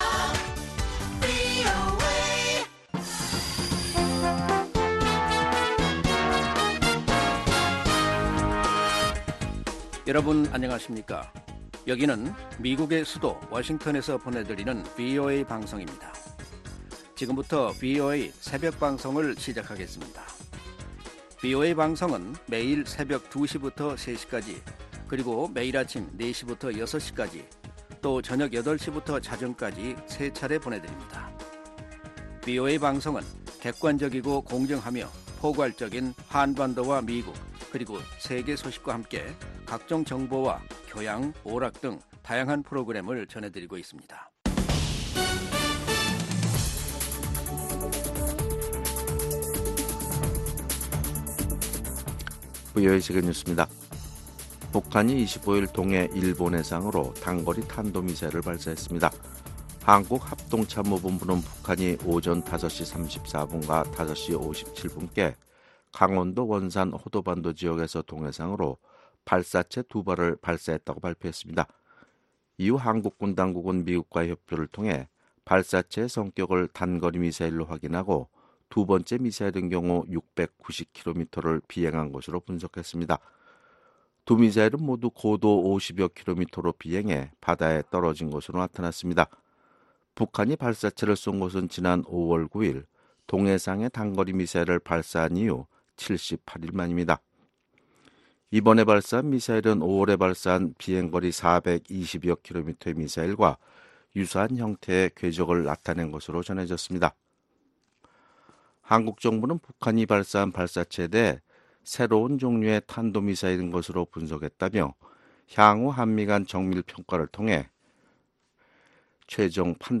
VOA 한국어 '출발 뉴스 쇼', 2019년 7월 26일 방송입니다. 한국 군 당국은 25일 북한이 두 번째로 발사한 단거리 미사일의 비행거리가 690여 km로 파악됐다고 밝혔습니다. 유엔주재 북한대표부는 미국이 안보리 대북 결의 이행을 유엔 회원국들에 촉구한 데 대해 “제재를 선동하고 있다”고 비난했습니다.